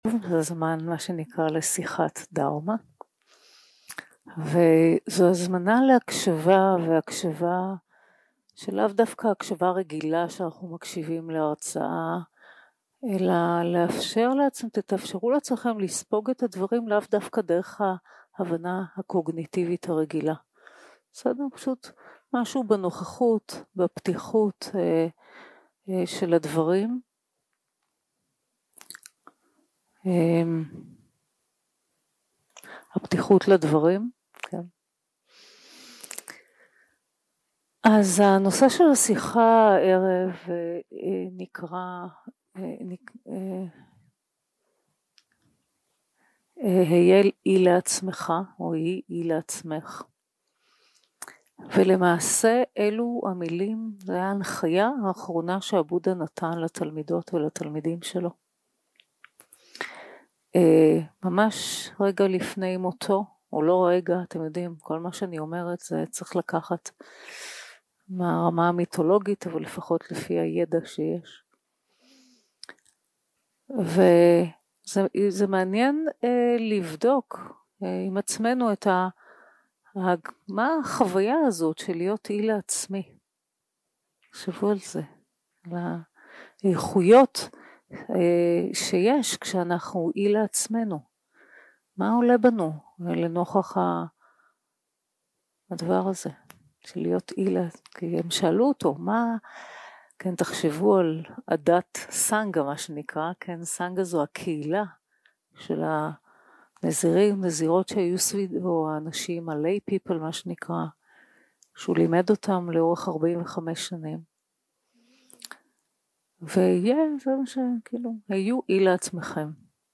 יום 2 - הקלטה 4 - ערב - שיחת דהרמה - היי אי לעצמך
Dharma Talks